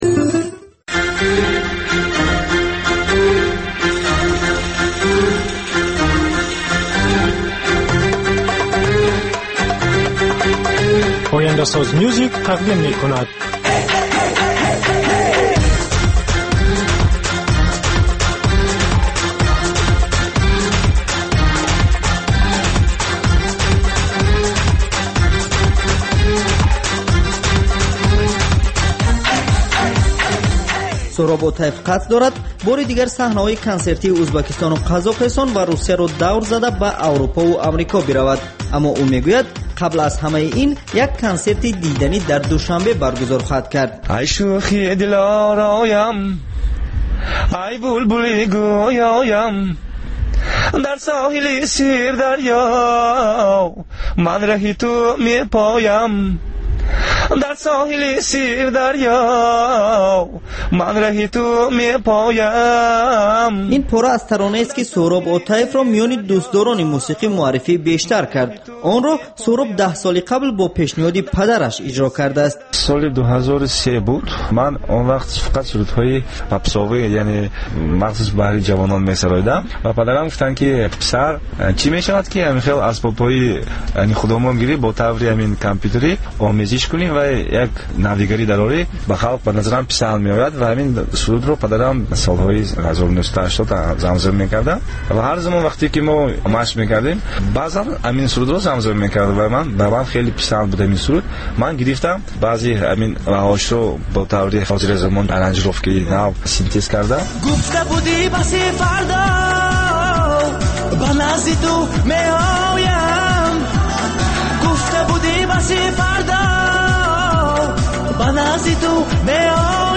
Барномаи мусиқӣ